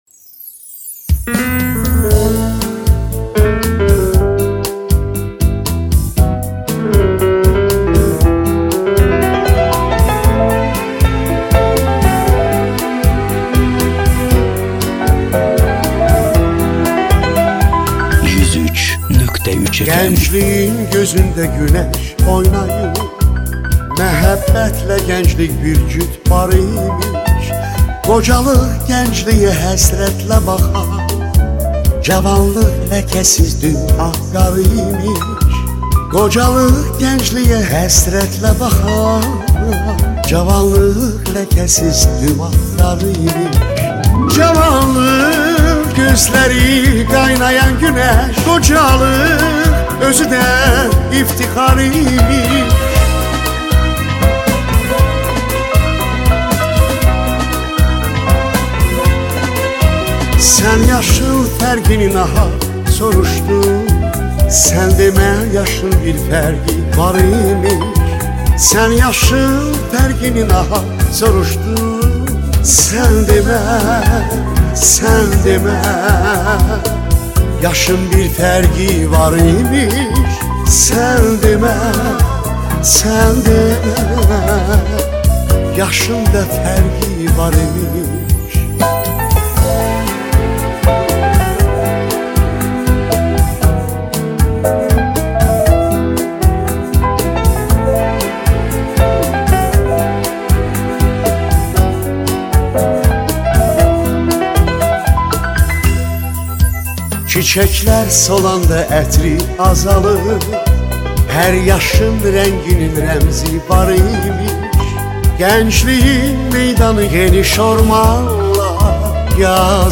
azeri